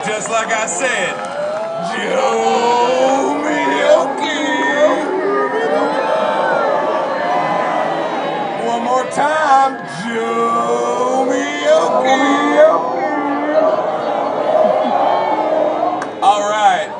Jomeokee Music & Arts Festival